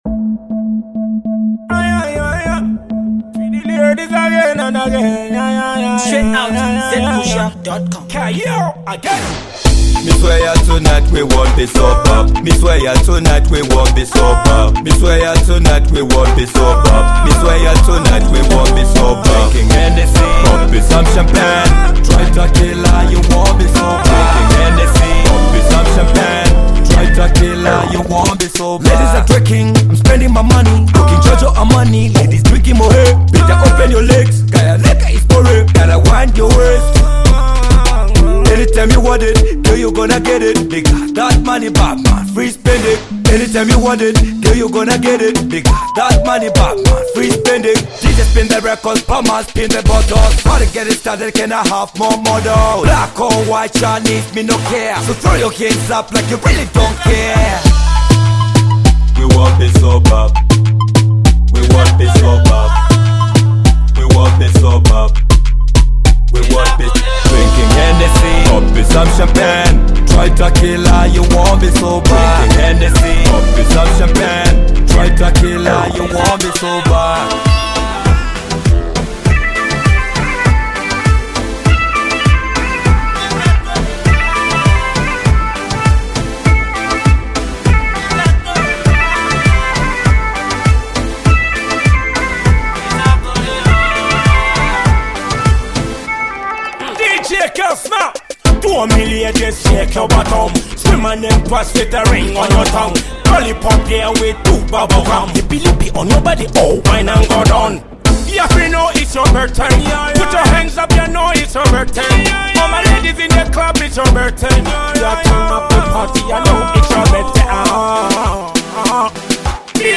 Dancehall
dope dance-able joint